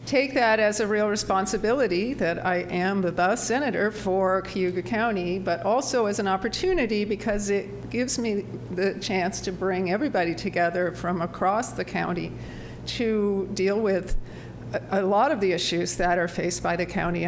State Senator Rachel was at Auburn’s City Council meeting Thursday night for her ceremonial swearing in as the senator for New York’s 48th senate district.